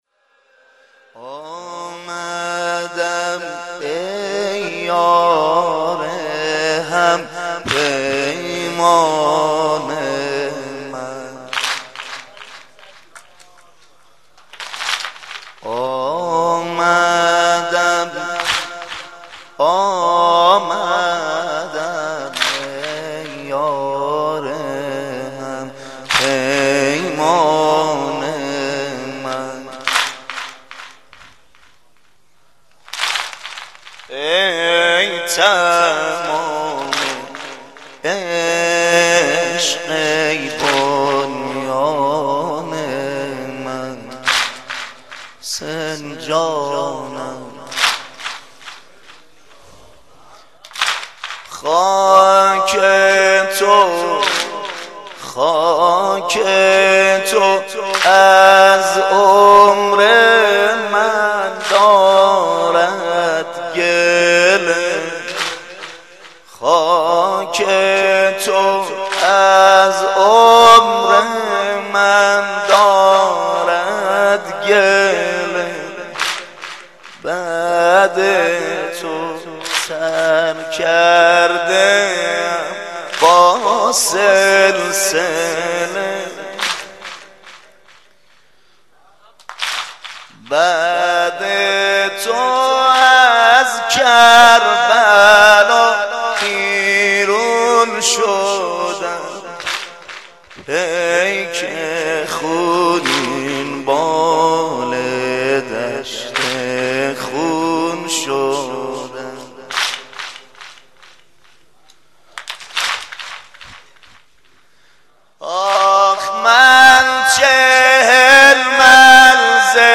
مناسبت : اربعین حسینی